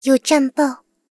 追猎者查看战绩语音.OGG